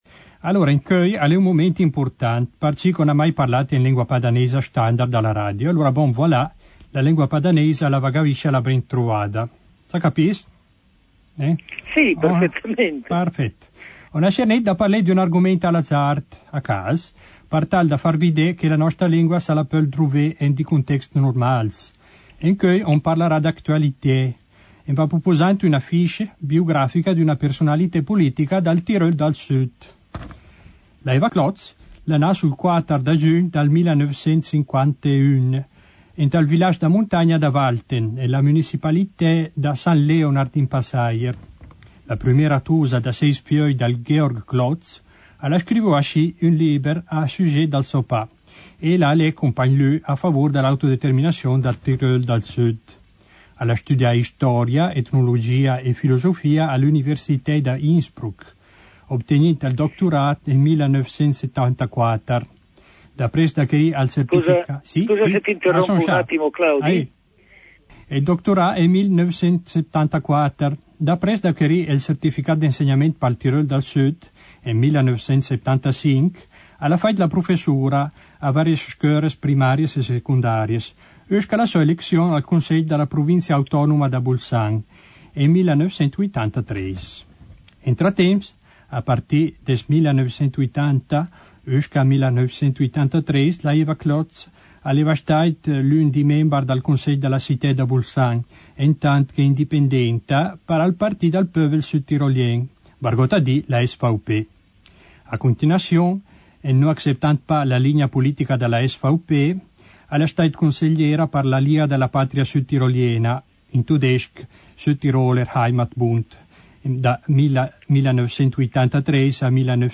La koiné Padaneisa pal prumer bot en Radio!